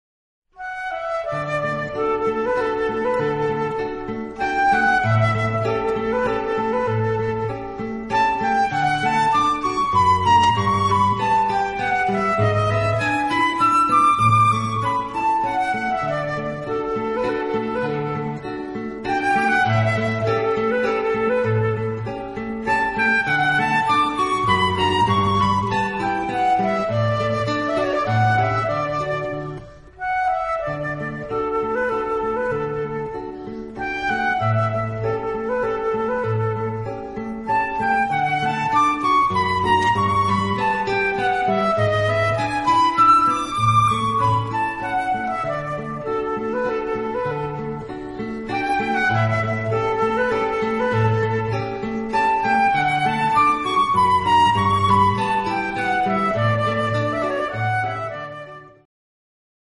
guitar Two seminal pieces for guitar and flute duet.